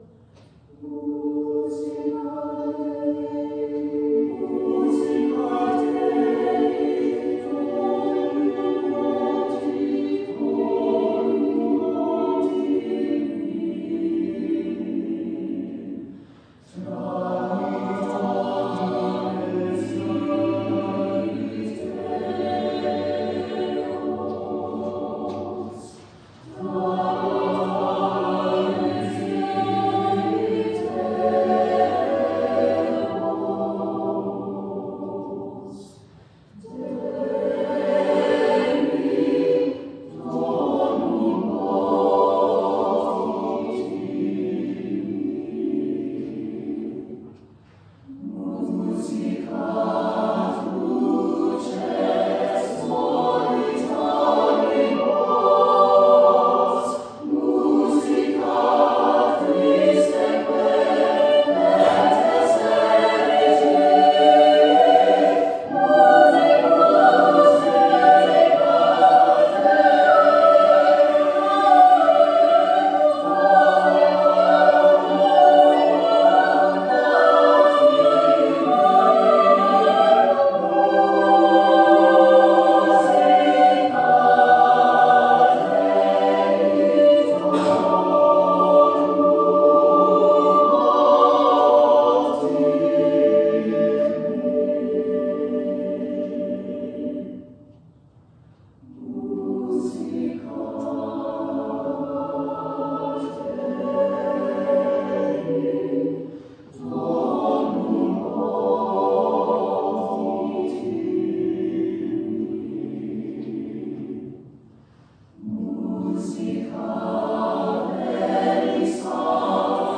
SATB Chorus with Divisi